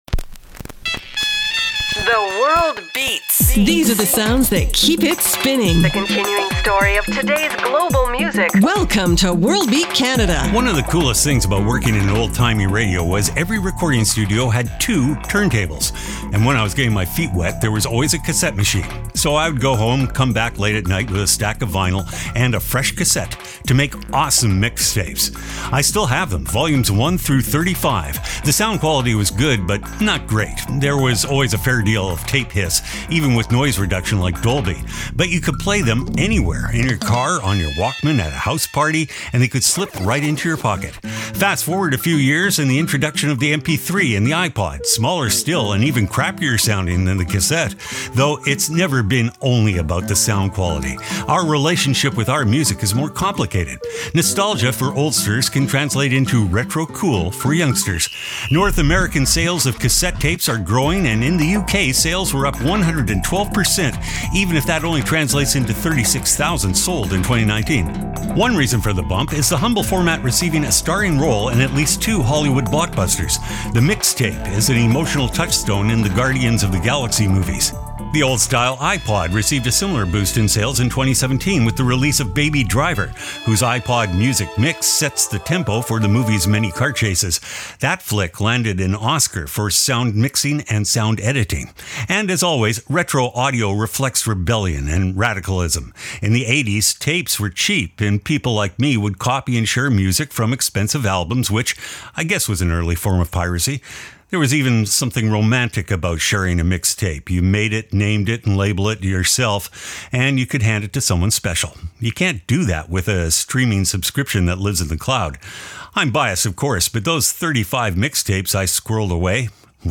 exciting global music alternative to jukebox radio